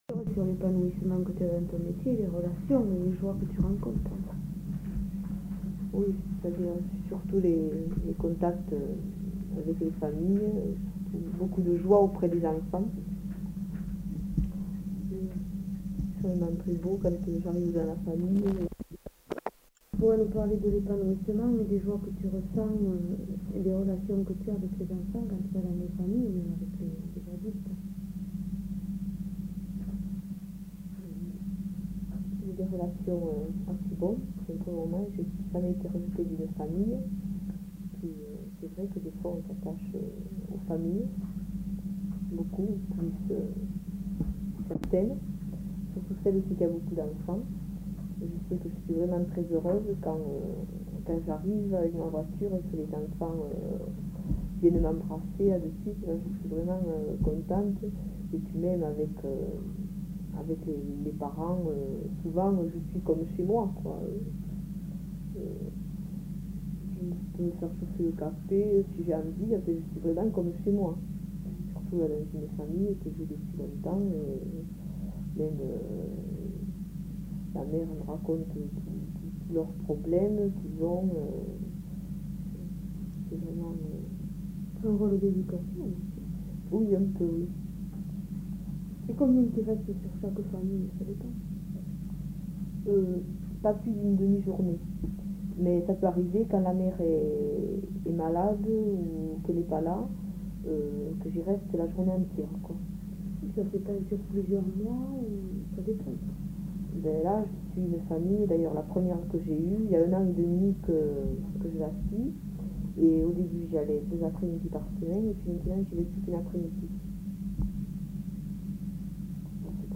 Lieu : Garravet
Genre : récit de vie